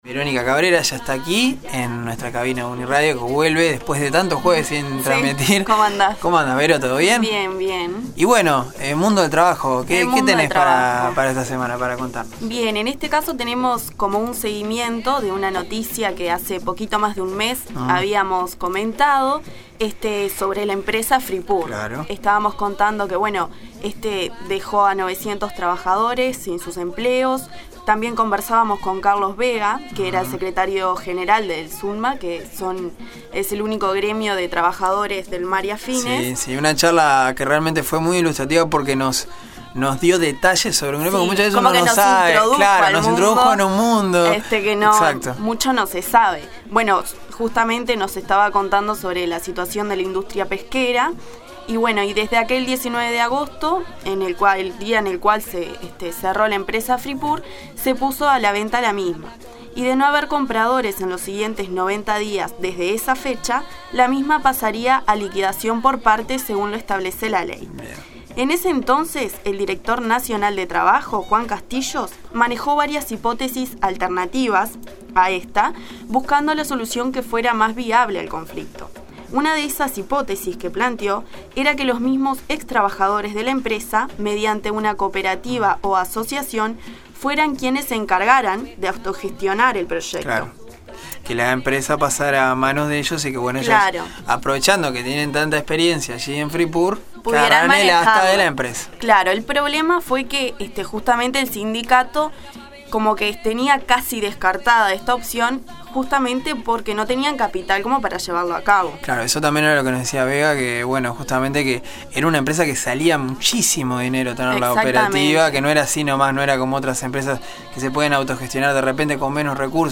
Para saber un poco más sobre esta nueva propuesta La Nueva Mañana dialogó con